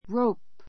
róup ロ ウ プ